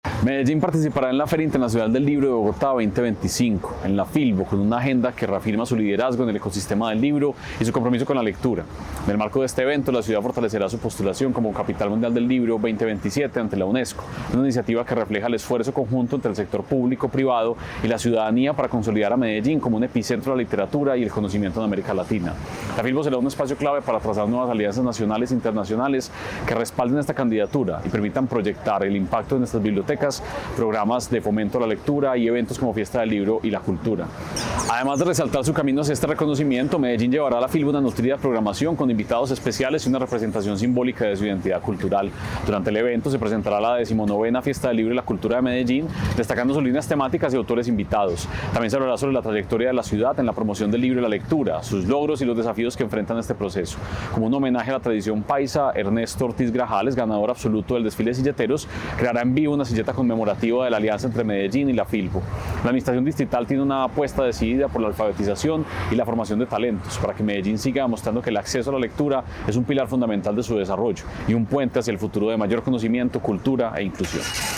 Declaraciones secretario de Cultura Ciudadana, Santiago Silva Jaramillo Medellín participará en la Feria Internacional del Libro de Bogotá (FilBo 2025), que en esta edición se hará del 25 de abril al 11 de mayo en Corferias.
Declaraciones-secretario-de-Cultura-Ciudadana-Santiago-Silva-Jaramillo.mp3